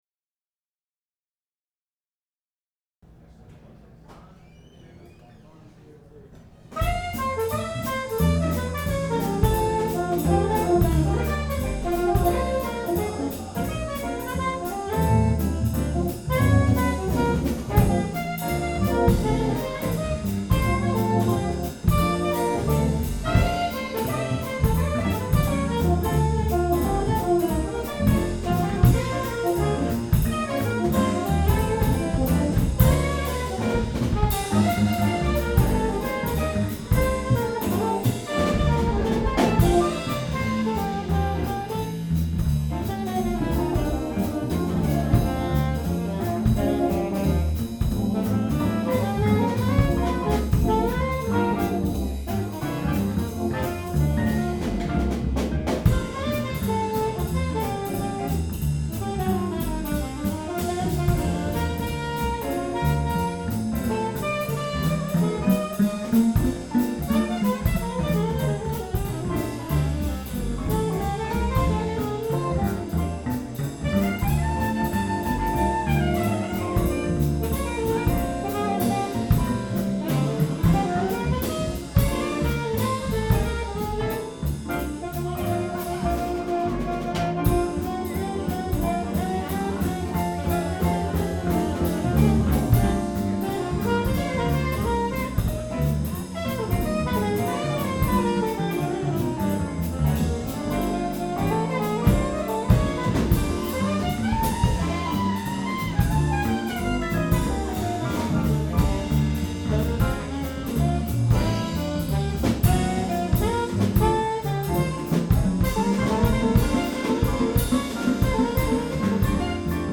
Jazz
Check out some of their arrangements --recorded on mini disc at Strawberry Fields coffee house in Potsdam. The room is small and not the best to play in. The recording quality is ok for mini disc and hopefully in the near future we can get a decent multi track recording in a good room to do their music a bit more justice.
I have to confess ignorance to the song titles most are from the Jazz Real Book and are standards: